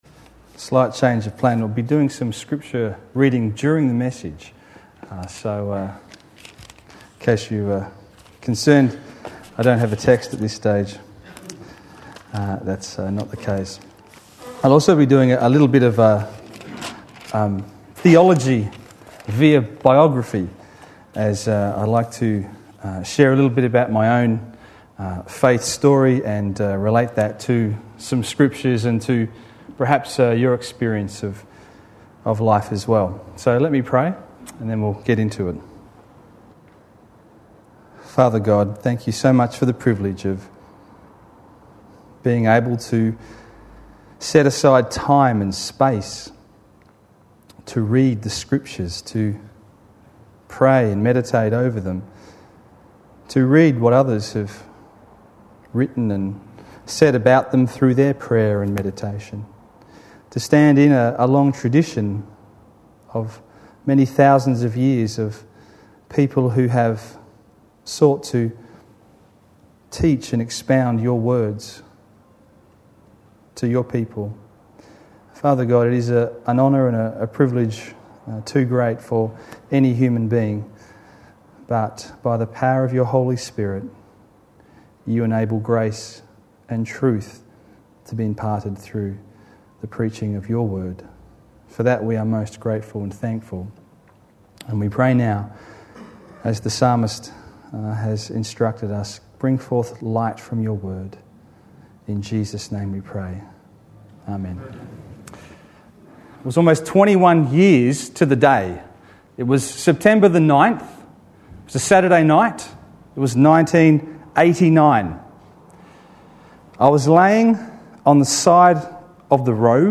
A sermon preached on 5th September, 2010.